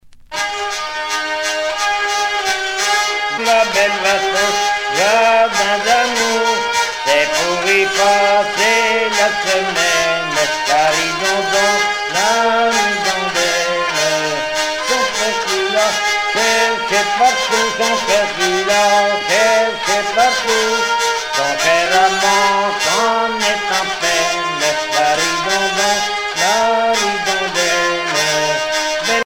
Genre laisse
Sonneurs de vielle traditionnels
Pièce musicale éditée